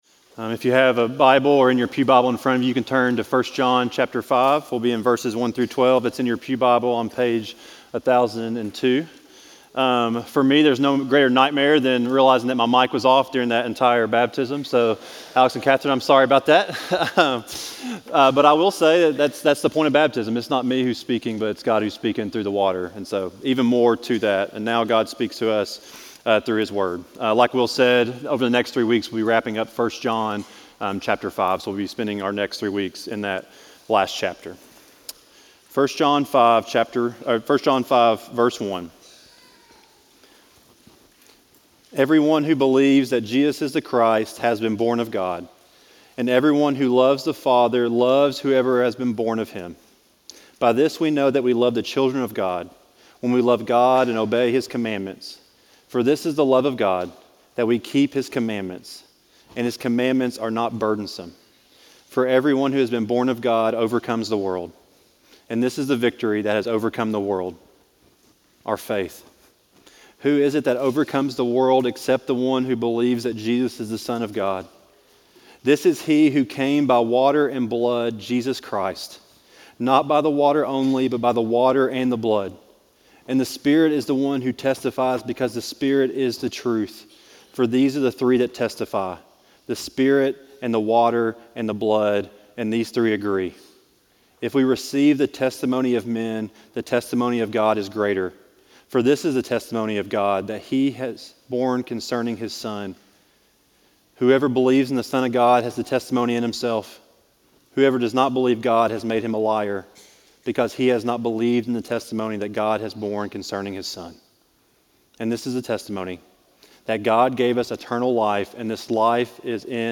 Sermons recorded during worship at Tates Creek Presbyterian Church (PCA) in Lexington, KY